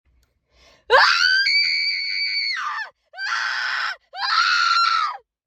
Sound Effects
Girl Screaming Bloody Murder